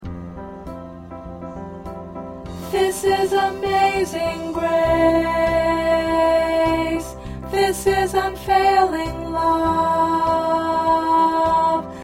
Alto part preview